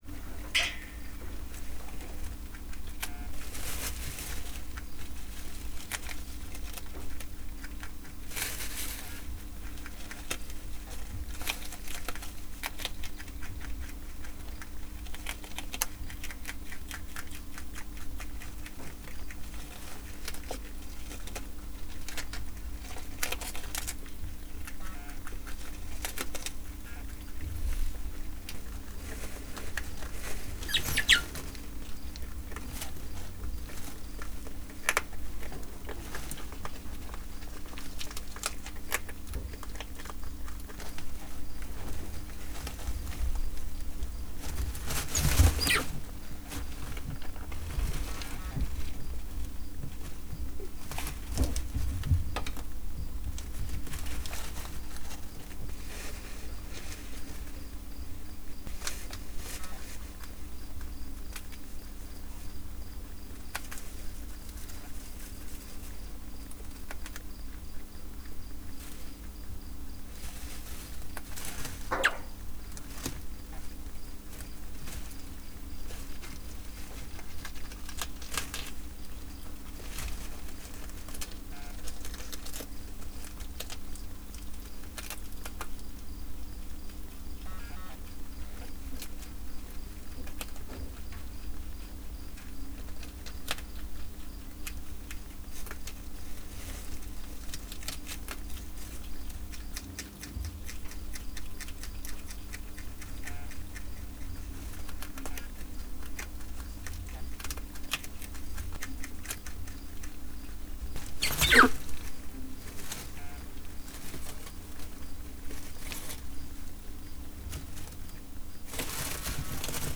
Directory Listing of //allathangok/miskolcizoo2009_standardt/nyugatitorpemokus/
esznekescivakodnak02.35.wav